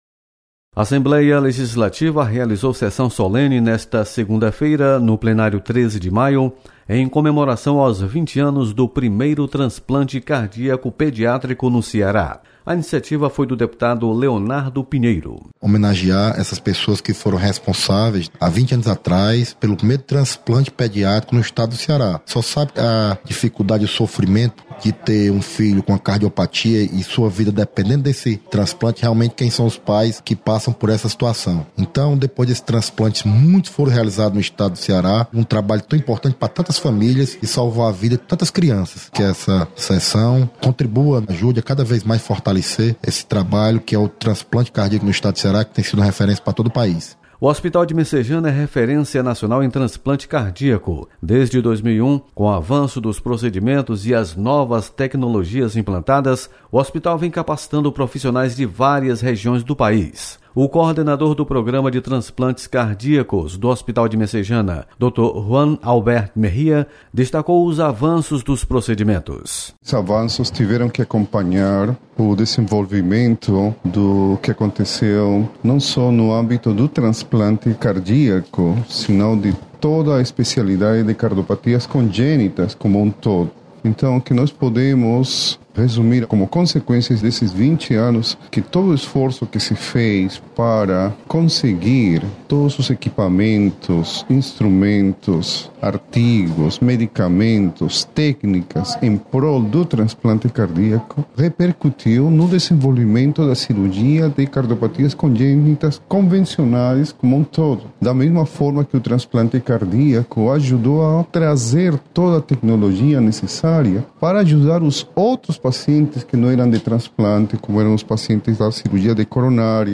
Solenidade comemora os 20 anos do primeiro transplante cardíaco em crianças. Repórter